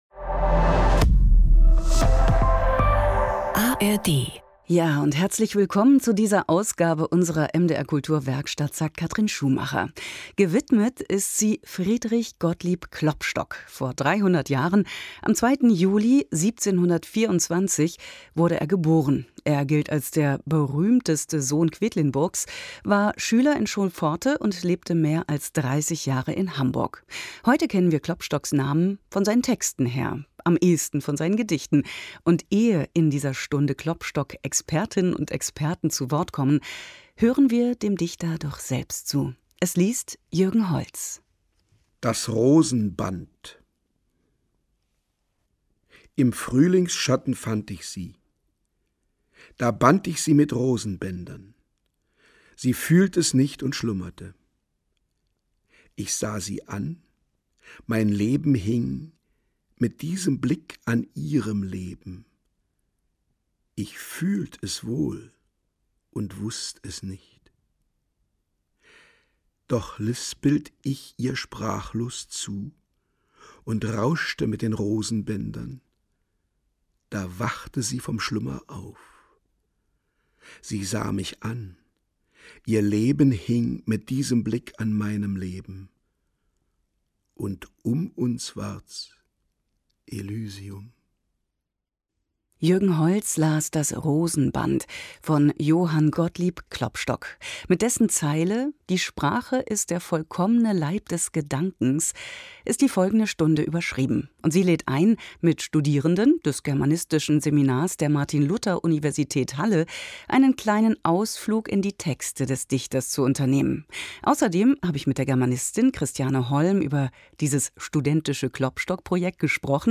Radiobeitrag "300 Jahre Klopstock